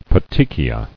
[pe·te·chi·a]